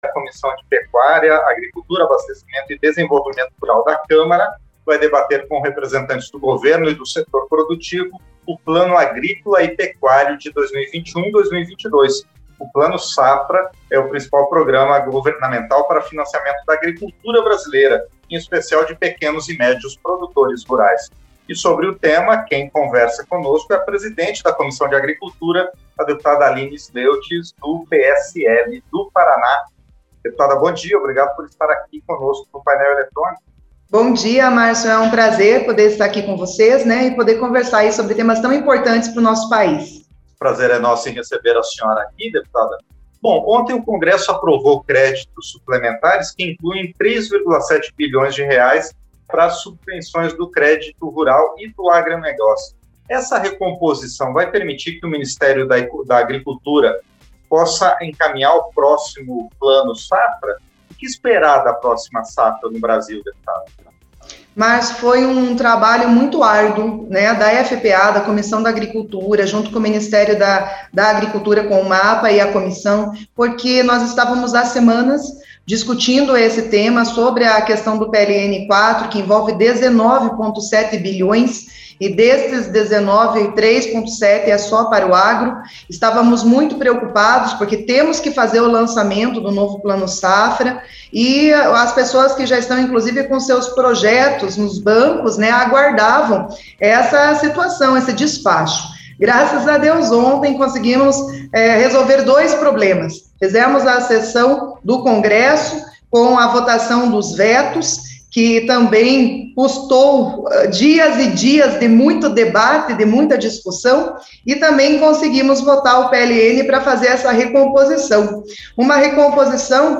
Entrevista - Dep. Aline Sleutjes (PSL-PR)